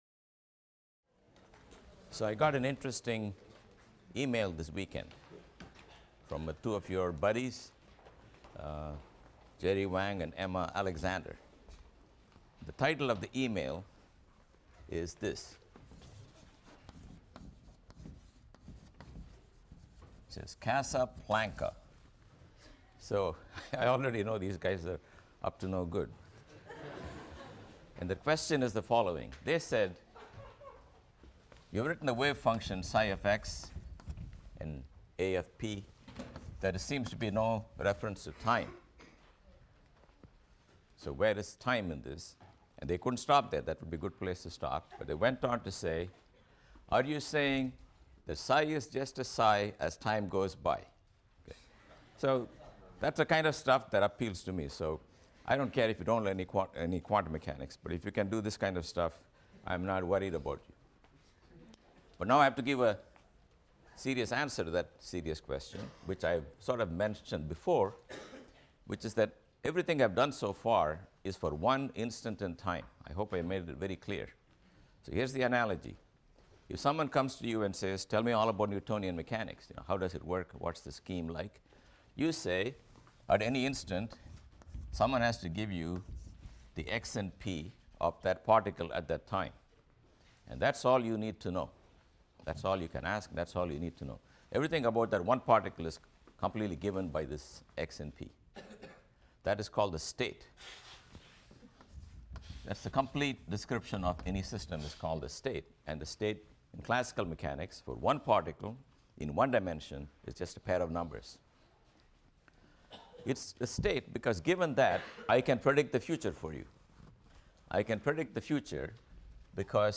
PHYS 201 - Lecture 22 - Quantum Mechanics IV: Measurement Theory, States of Definite Energy | Open Yale Courses